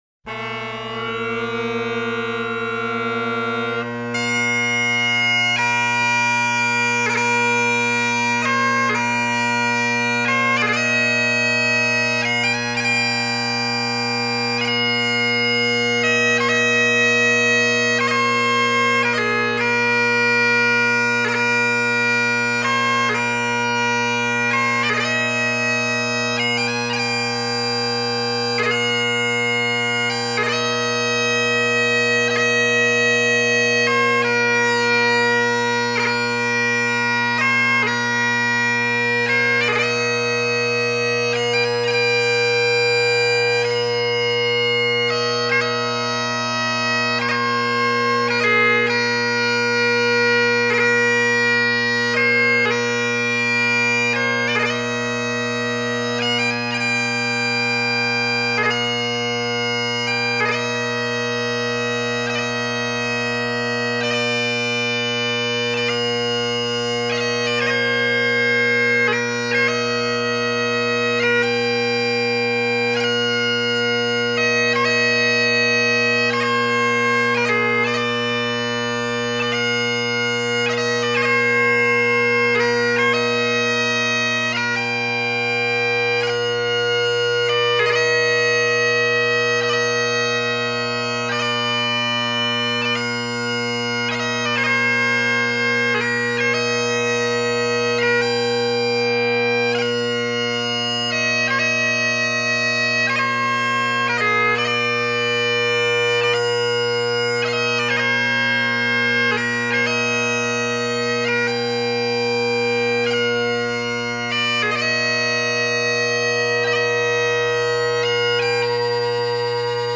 The haunting Flowers of the Forest
and described as one of the finest bagpipe players in the world.